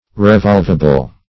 Revolvable \Re*volv"a*ble\, a.